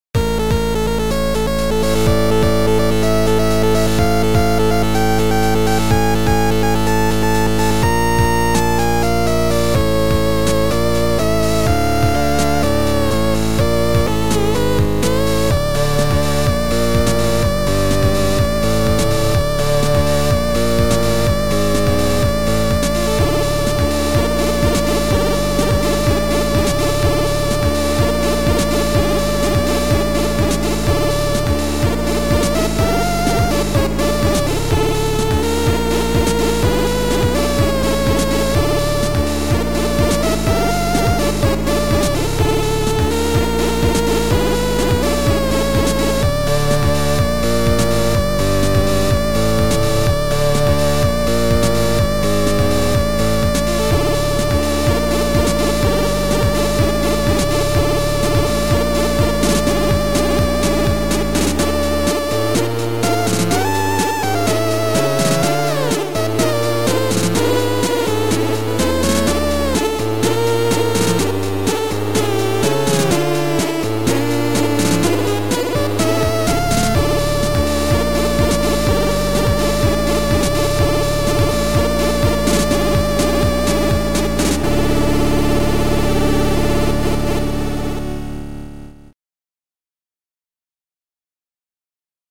Sound Format: Noisetracker/Protracker
Chip Music